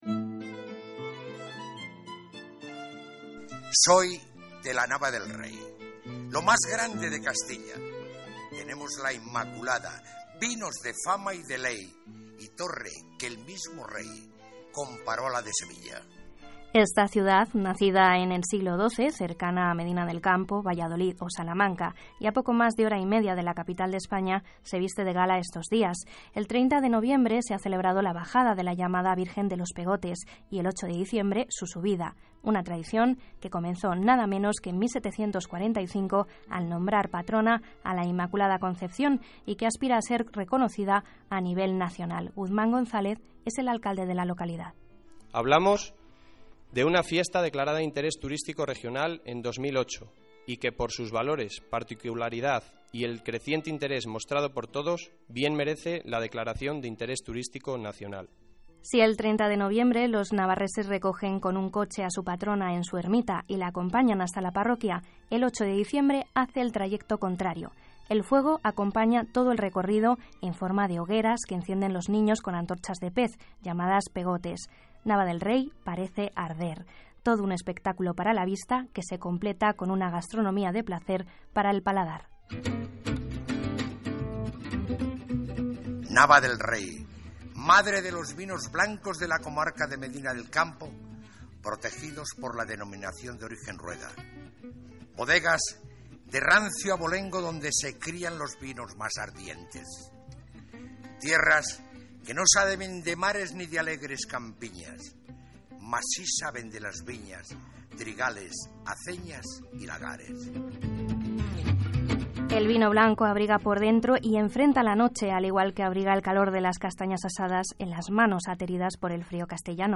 Reportaje de la Virgen de los Pegotes emitido por RNE